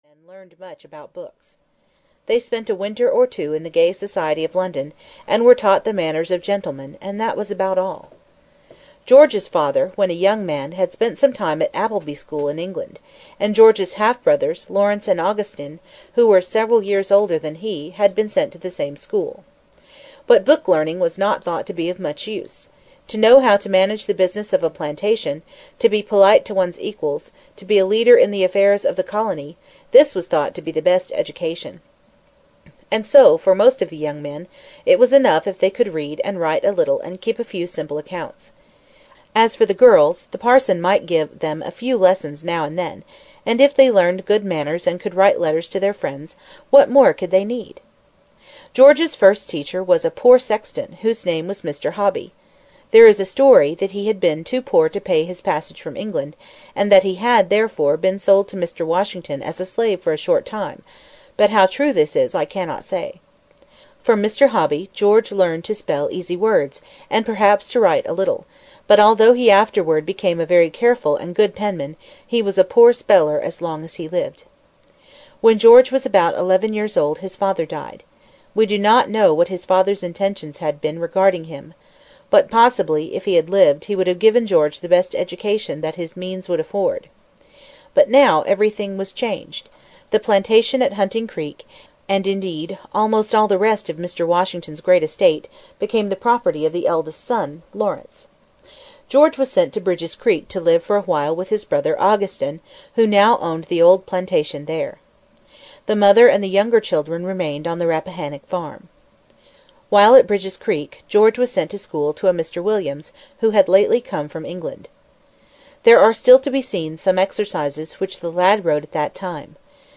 Four Great Americans (EN) audiokniha
Ukázka z knihy